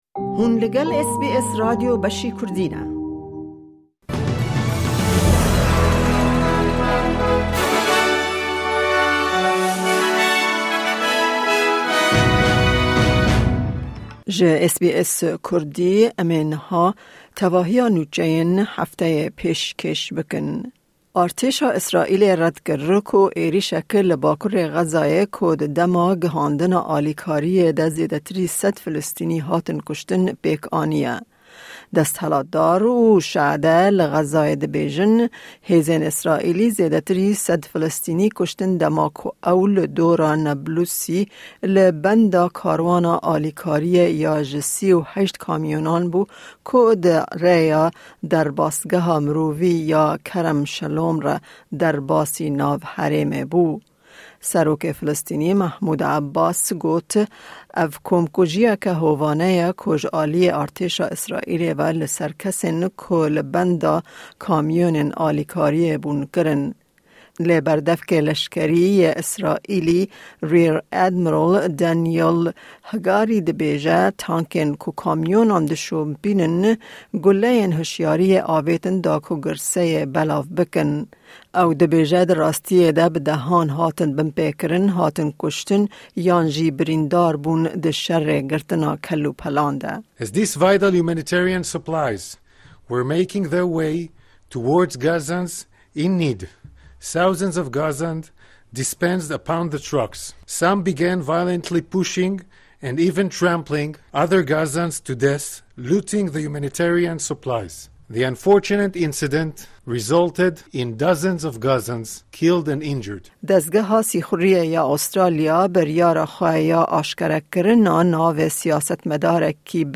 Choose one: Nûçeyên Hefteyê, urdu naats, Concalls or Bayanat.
Nûçeyên Hefteyê